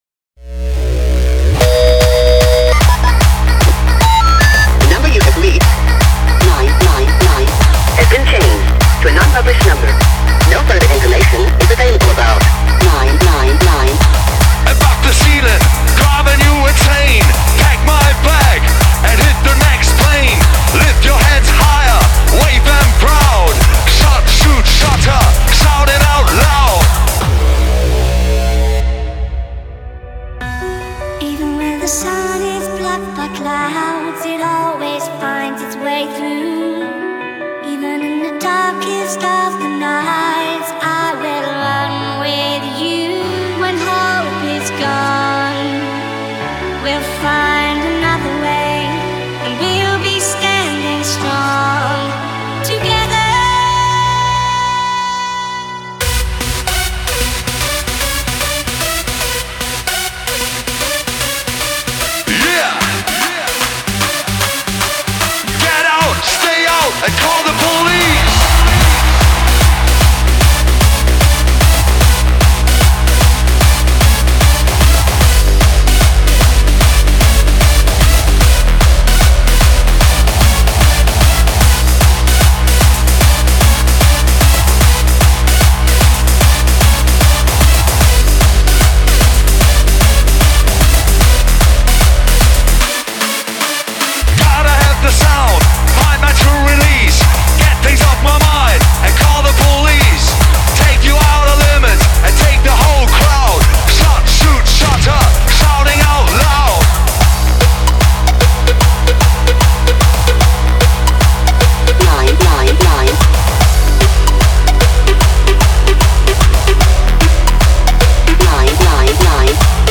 BPM75-150
MP3 QualityMusic Cut